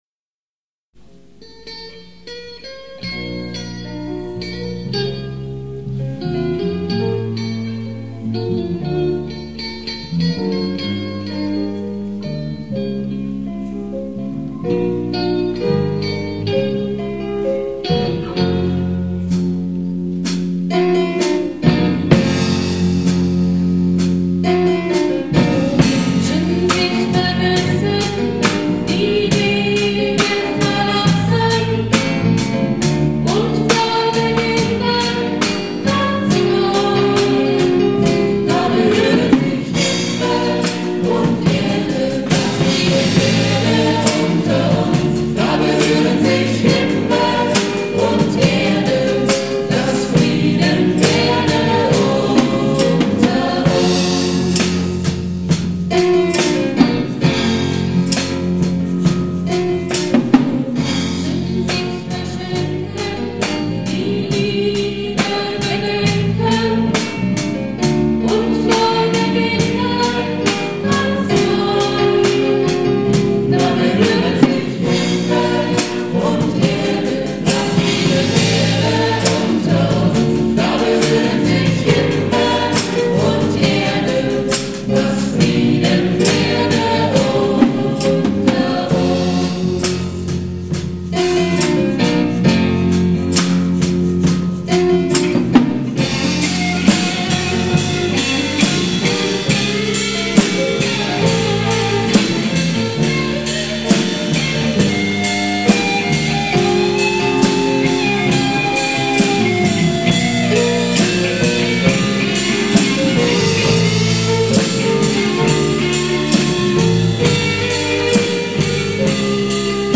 alle Aufnahmen sind "live" in der Kirche mitgeschnitten und deshalb keine CD-Qualität